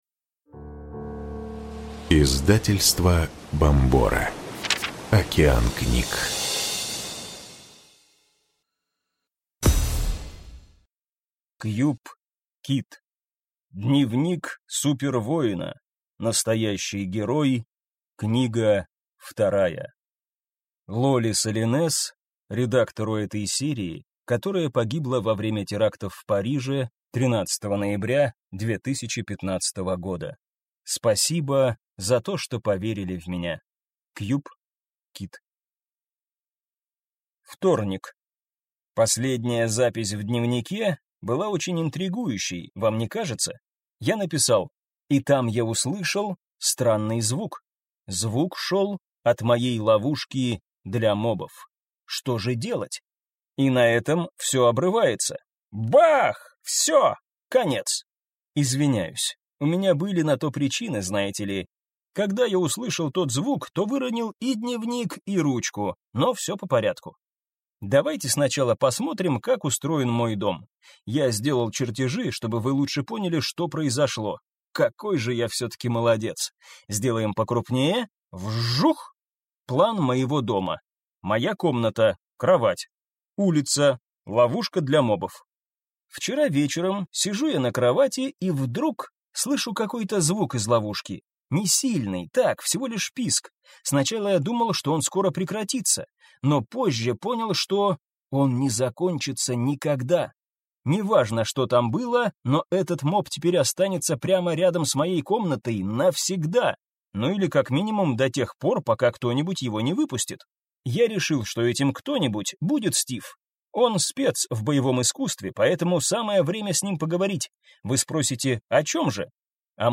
Аудиокнига Дневник супервоина. Настоящий герой!